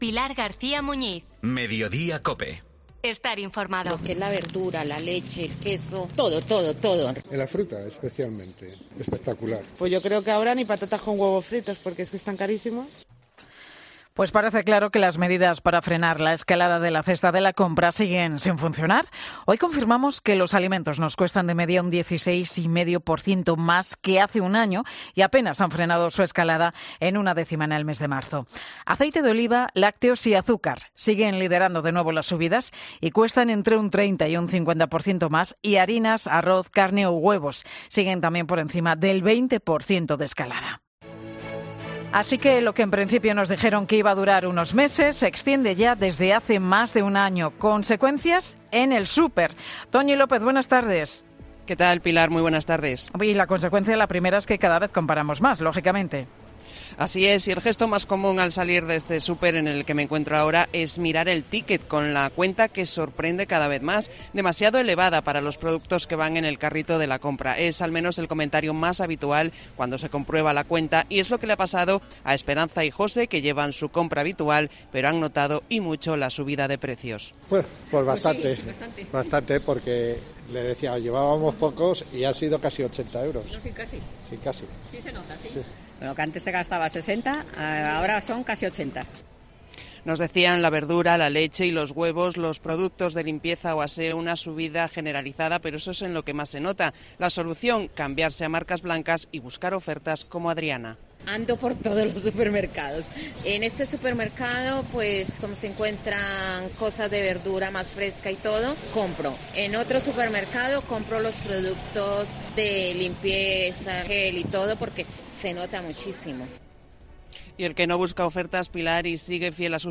La leche, los huevos o los productos de limpieza son los productos que más han subido y los oyentes de 'Mediodía COPE' nos cuentan cómo sortean esta subida
Pues en Mediodía COPE nos trasladamos hasta un supermercado para conocer, de primera mano, cómo está afectando esta subida a los usuarios.